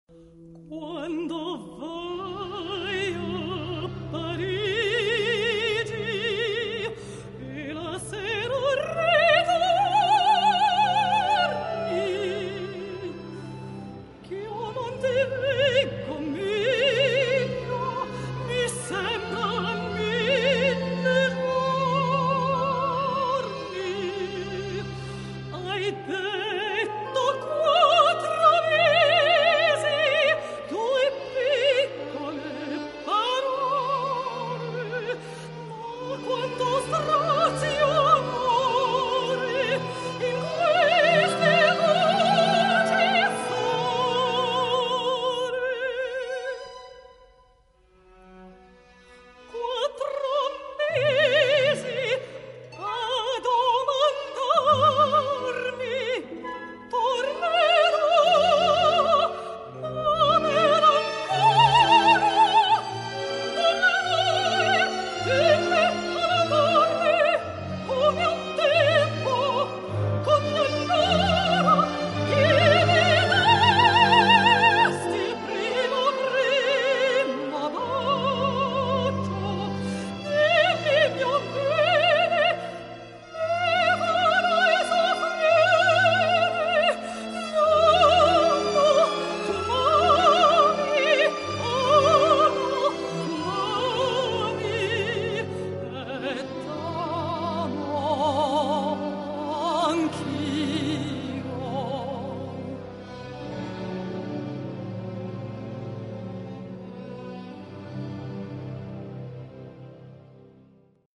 Zazà [Sopran]